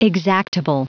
Prononciation du mot exactable en anglais (fichier audio)